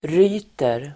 Uttal: [r'y:ter]